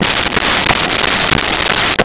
Saw Wood 03
Saw Wood 03.wav